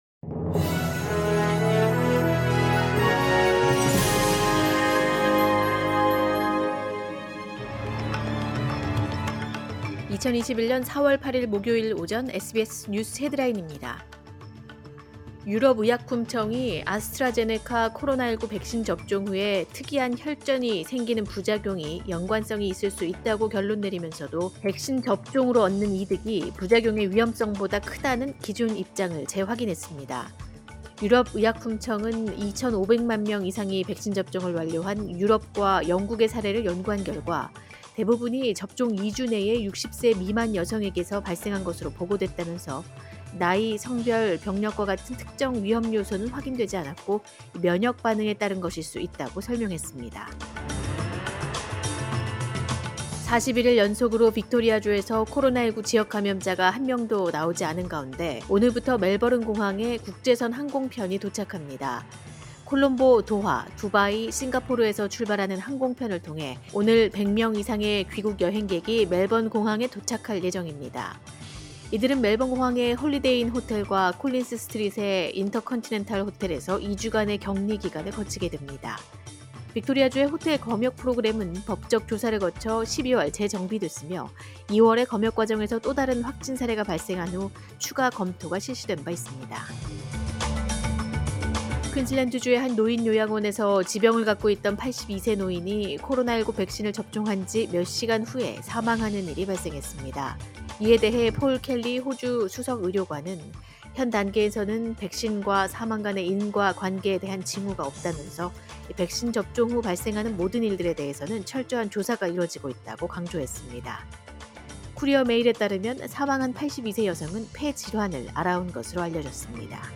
2021년 4월 8일 목요일 오전의 SBS 뉴스 헤드라인입니다.